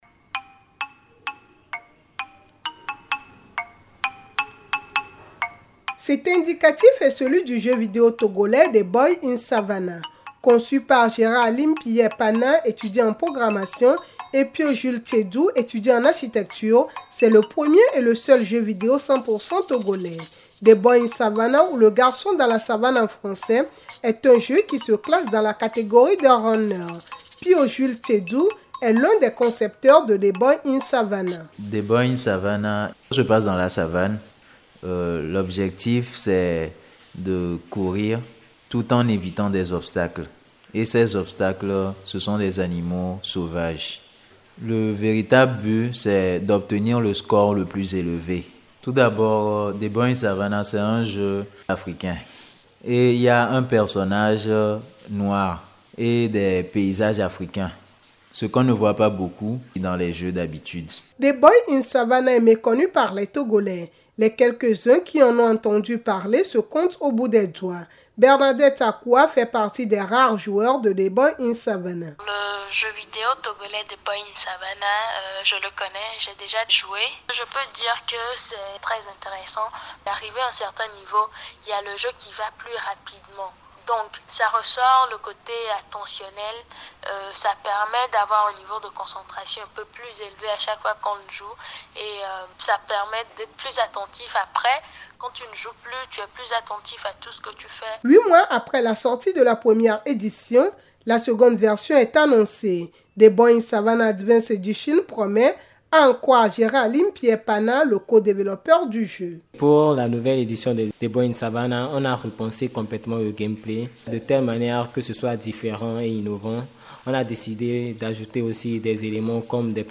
Cet indicatif est celui du jeu vidéo togolais, « the boy in savannah ».
Reportage